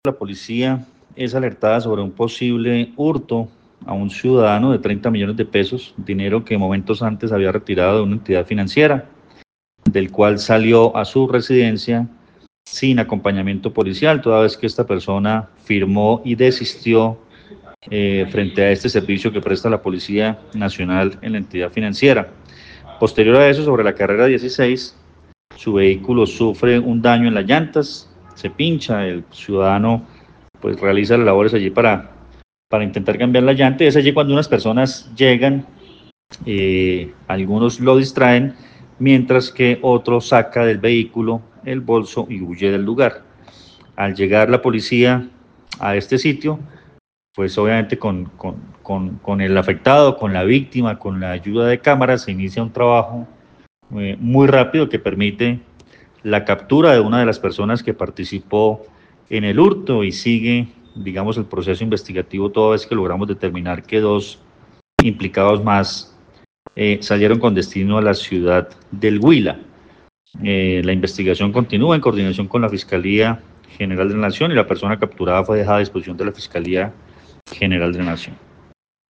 Coronel Luis Fernando Atuesta, comandante de la Policía del Quindío.